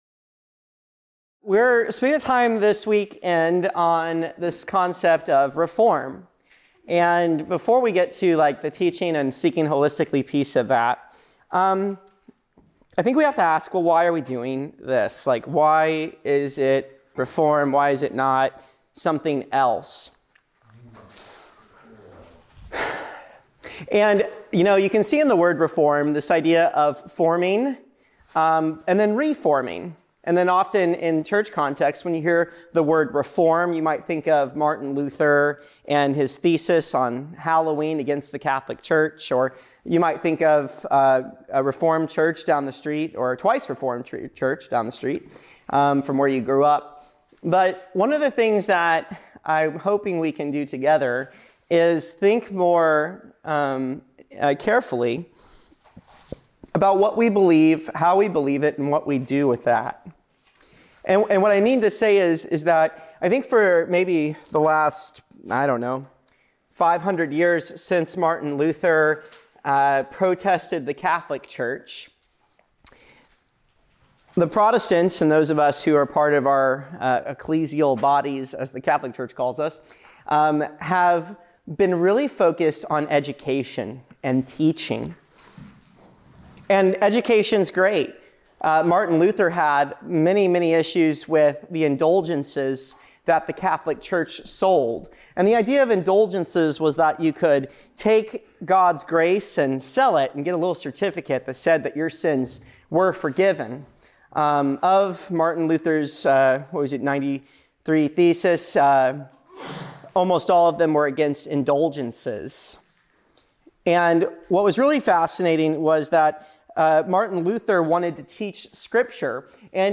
First of our teachings from the 2025 Garden Church Retreat walking through our mission statement: We live out our vision by seeking Jesus holistically, loving each other authentically, serving humbly, and sending missionally.